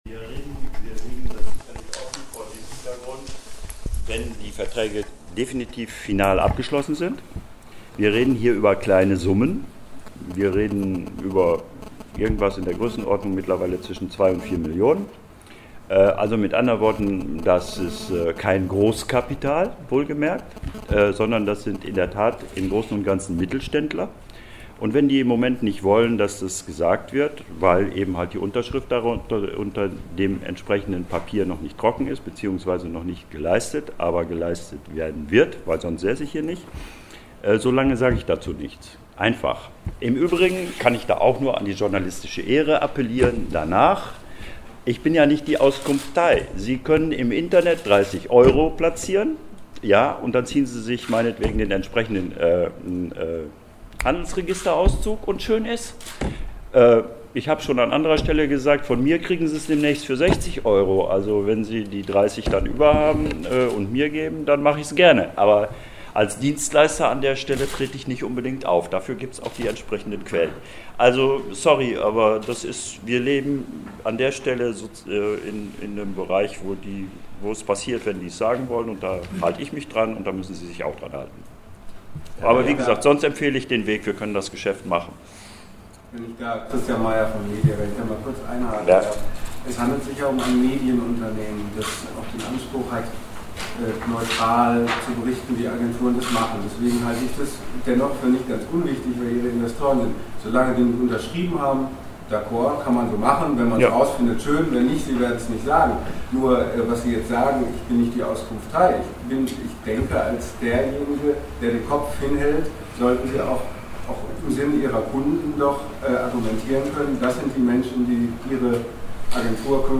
Pressekonferenz zur Herausführung der dapd aus der Insolvenz